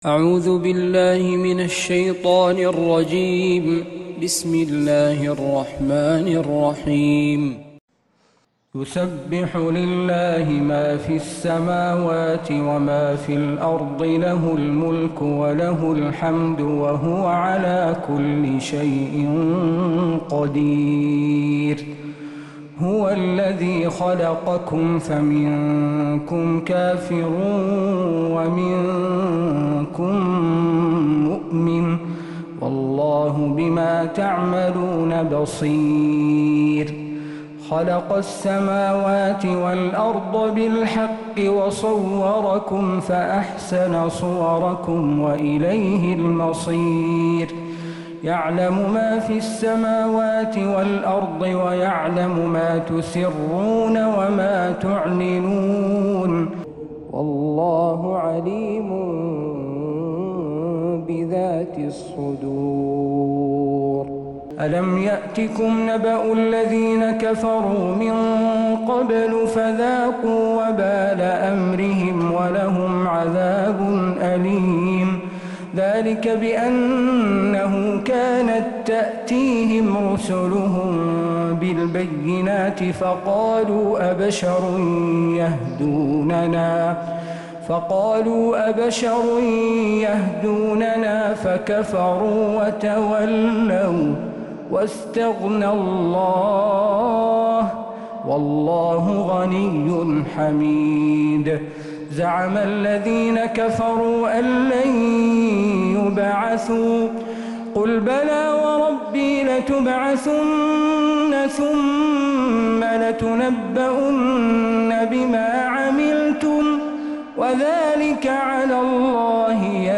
سورة التغابن كاملة من فجريات الحرم النبوي